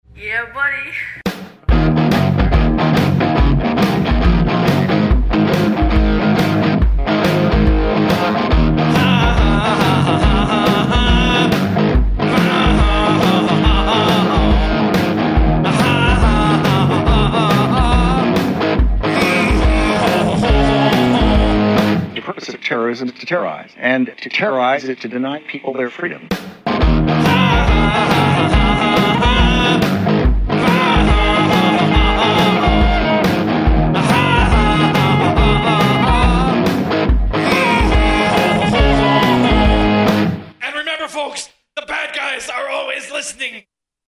Fourteen cuts of war fever classic rock